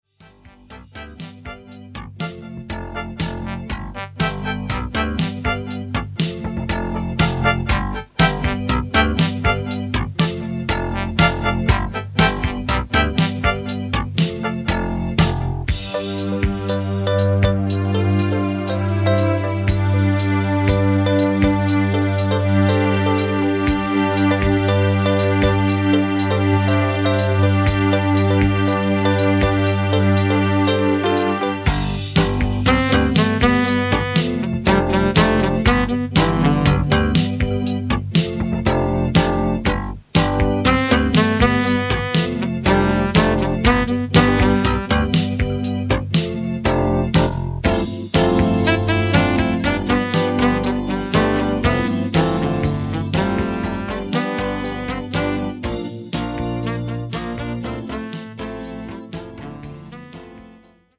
Strong times are marked and incite us to mark the thing…
Violins will come to perfect the melody and the orchestra…